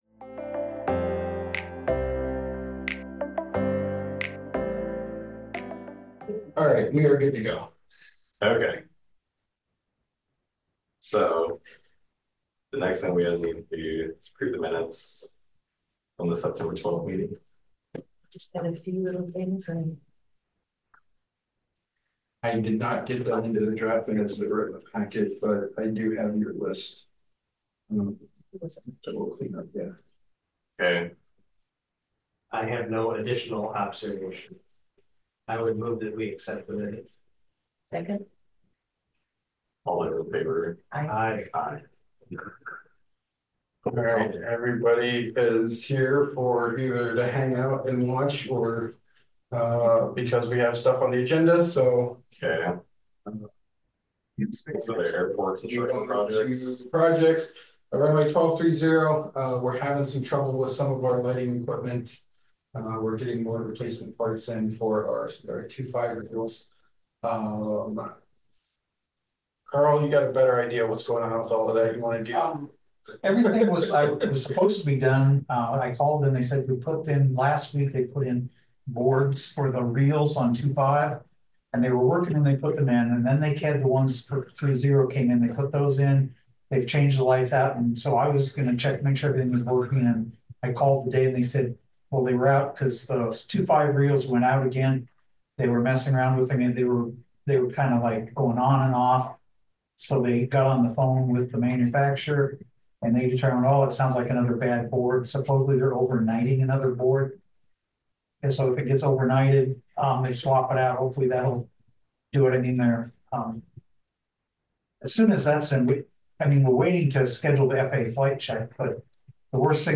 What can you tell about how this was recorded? Regular monthly meeting of the City of Iowa City's Airport Commission, rescheduled from October 10.